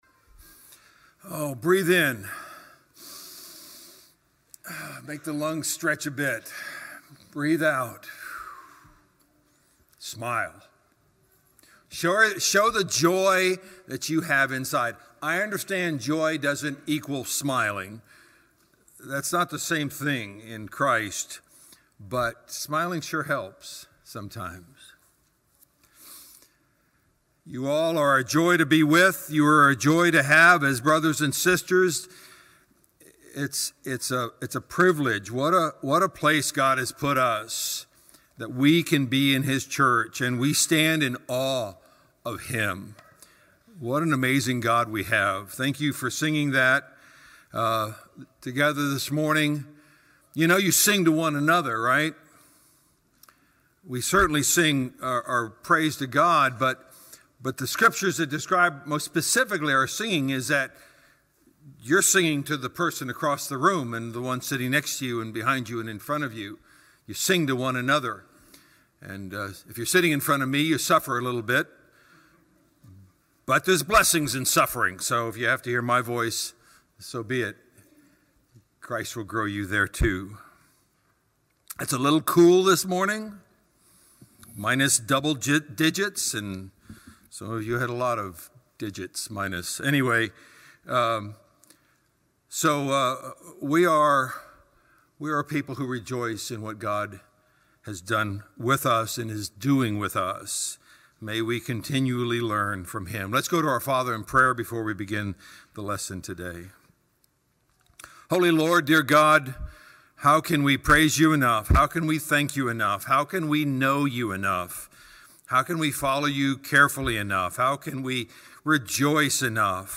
Valley church of Christ - Matanuska-Susitna Valley Alaska
Home Audio Audio Sermons Sermons - 2023 What is Our Purpose?